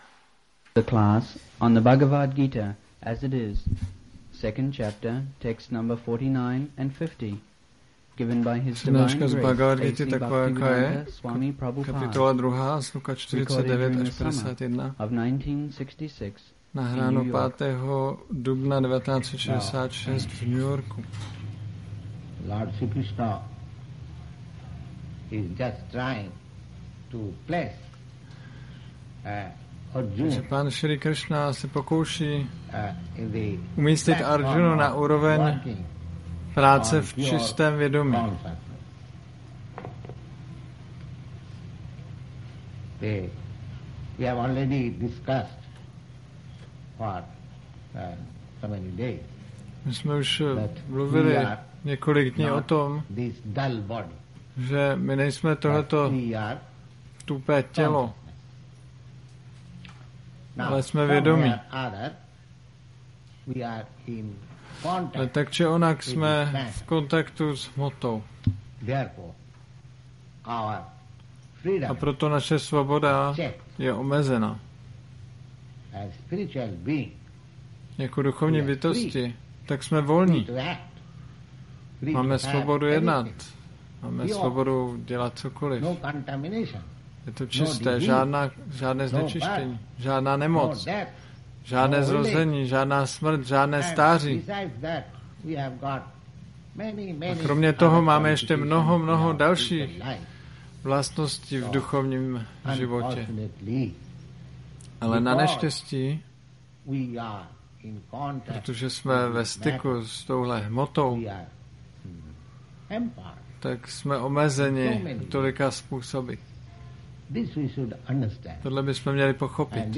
1966-04-05-ACPP Šríla Prabhupáda – Přednáška BG-2.49-51 New York